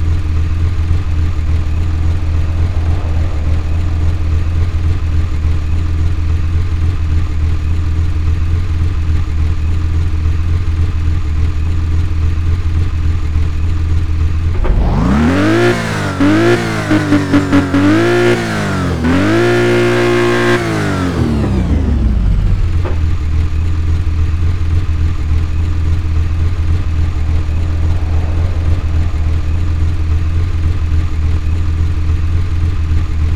Index of /server/sound/vehicles/lwcars/lotus_esprit
idle.wav